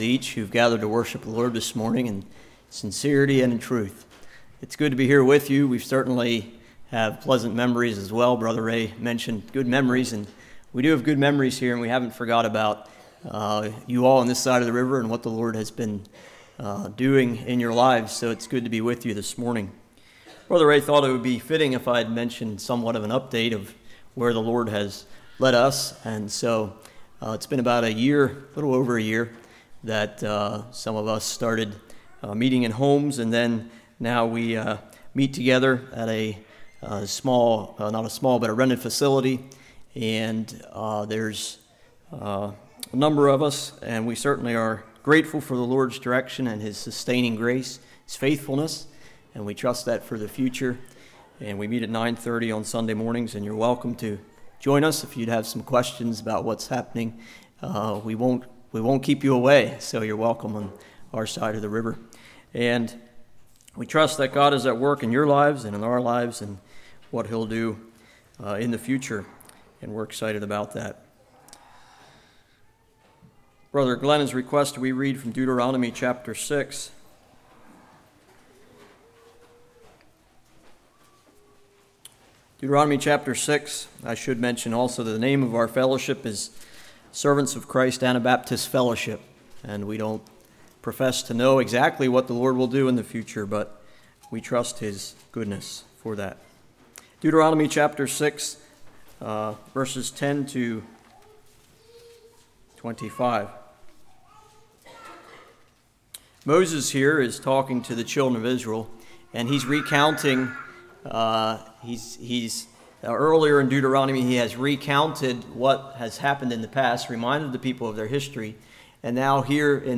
Passage: Deuteronomy 6:10-25 Service Type: Morning Israel Complains Fear God or Man?